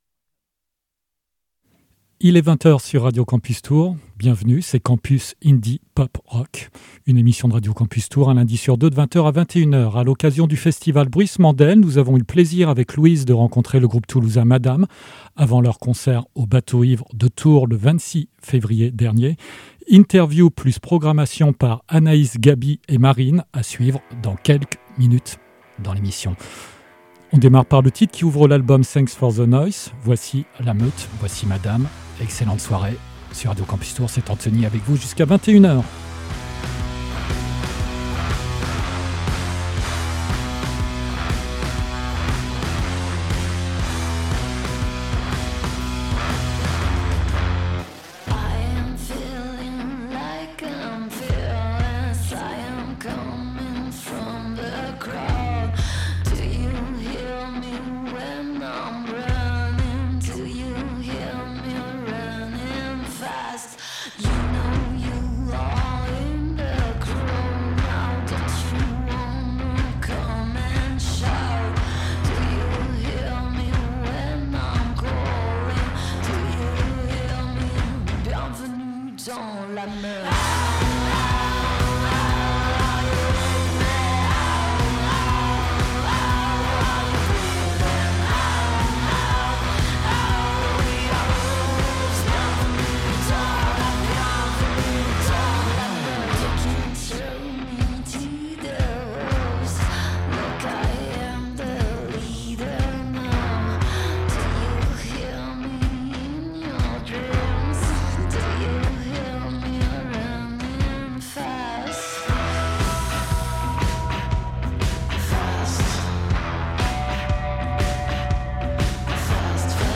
Campus Indie Pop Rock, une émission de Radio Campus Tours, un lundi sur deux de 20h à 21h.
A l’occasion du festival Bruissements d’Elles nous avons rencontré le groupe toulousain MADAM avant leur concert au Bateau Ivre de Tours. Interview